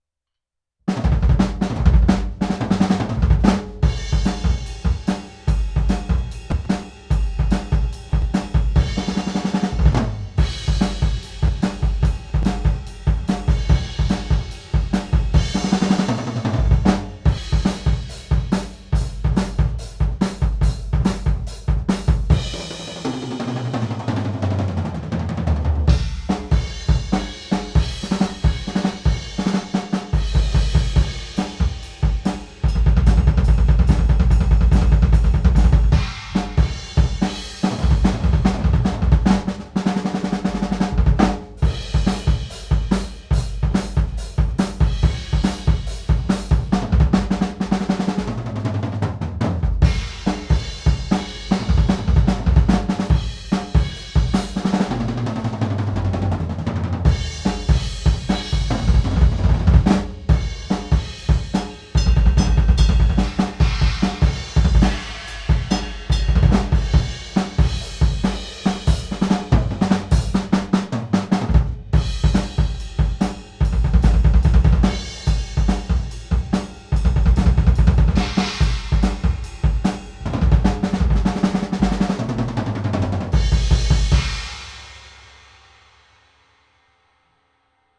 Drum Samples
KUHLDRUMZ_DrumSolo1.wav